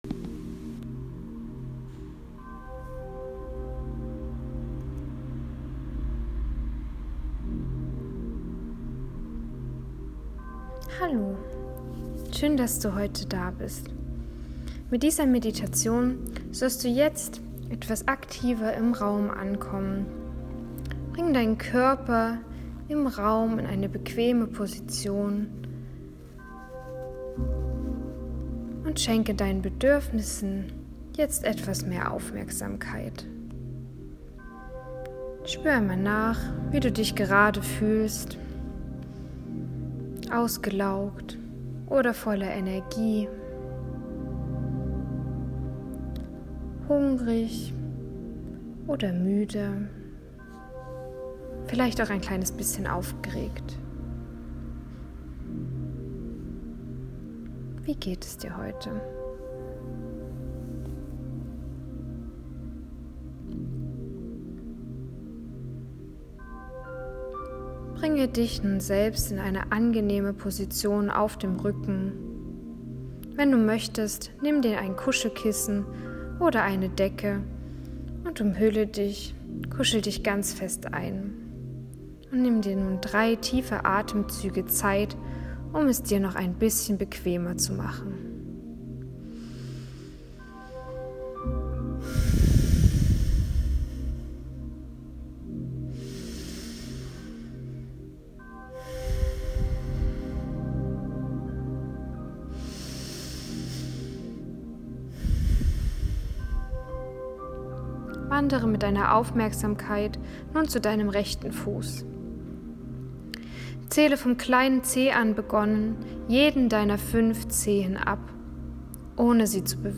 Sie hat kein klares Ende, denn dieses sollst du dir selbst erfühlen.
Self-Love-Meditation.m4a